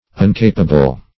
uncapable - definition of uncapable - synonyms, pronunciation, spelling from Free Dictionary Search Result for " uncapable" : The Collaborative International Dictionary of English v.0.48: Uncapable \Un*ca"pa*ble\, a. Incapable.